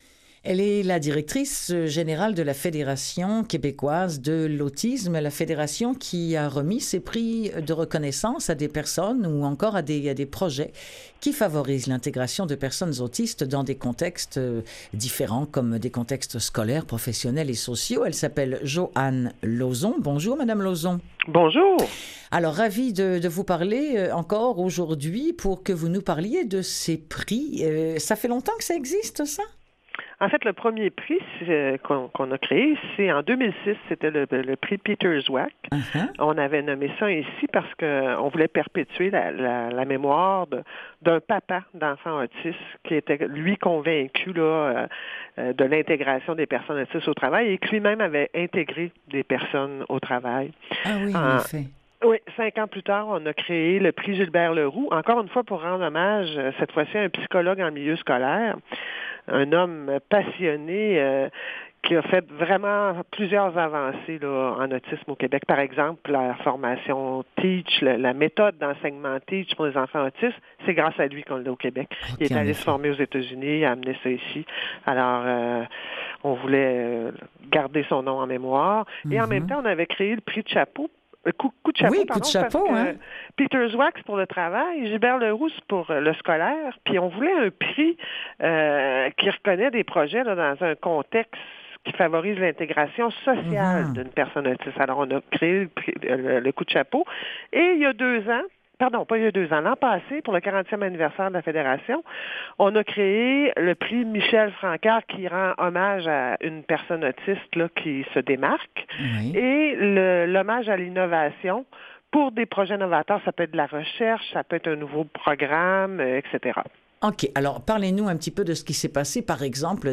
LES ENTREVUES DU JOUR